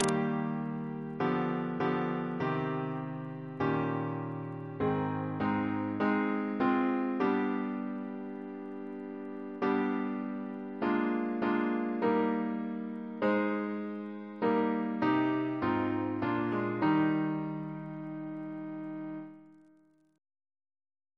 CCP: Chant sampler
Double chant in E♭ Composer: Thomas Attwood (1765-1838), Organist of St. Paul's Cathedral Reference psalters: ACB: 204; CWP: 85; OCB: 212; RSCM: 8